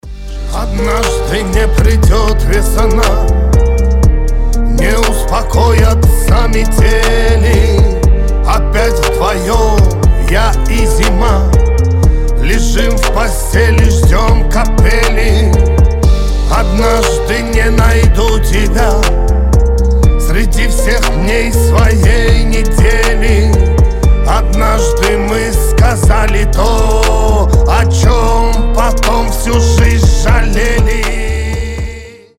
• Качество: 320, Stereo
лирика
грустные
медленные